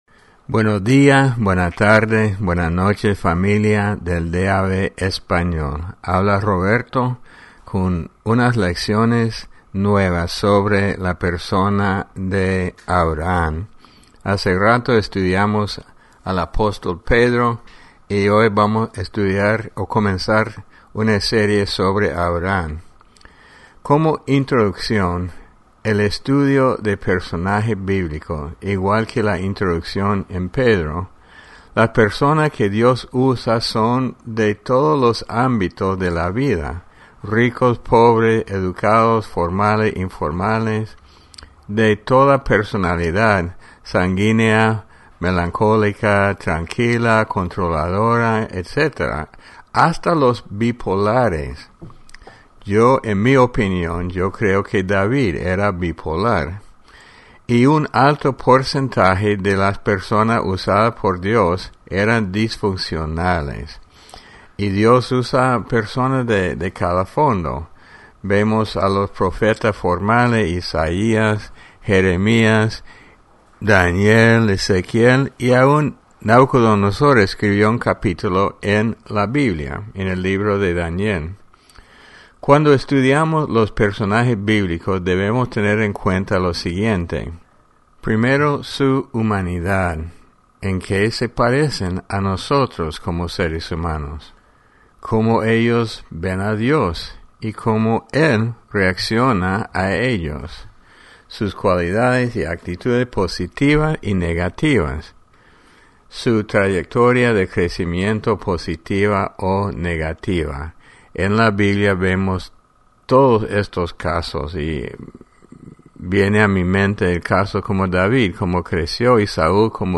Lección 01 Abraham – El Padre inseguro de la Fe